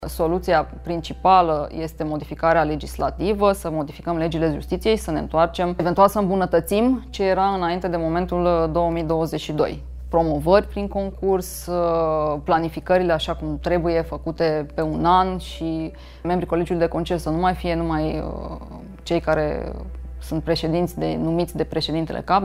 Judecătoarea Raluca Moroşanu, de la Secția I Penală, reclamă, într-un interviu acordat Recorder, că magistraţii nu sunt consultaţi sau implicaţi în deciziile luate de conducere, iar planificările sunt modificate fără a fi anunţaţi.